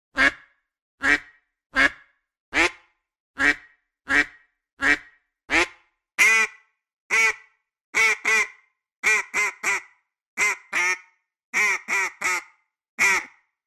근처에 가면 오리 소리가 납니다. (거리 위치에 따라서 소리가 달라집니다.)